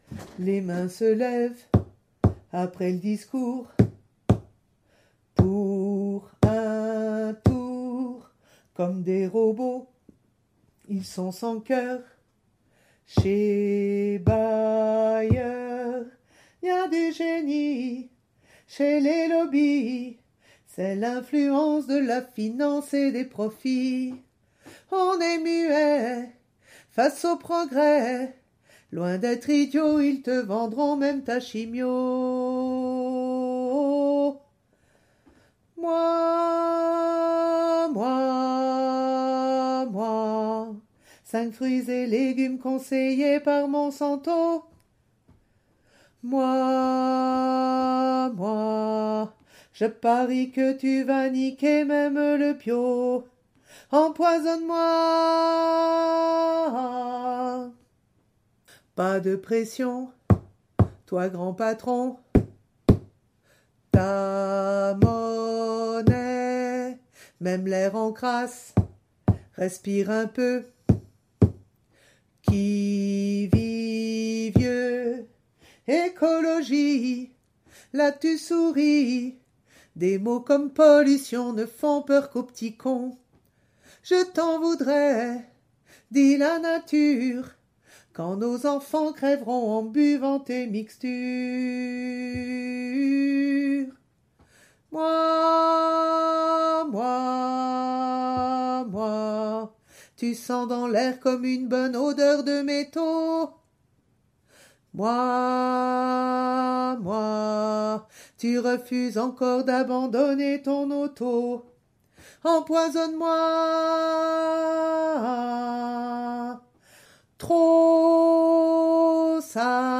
–> Arrangement à 3 voix : Soprano Mezzo Basse